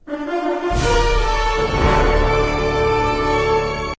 8. Спайдермен эффект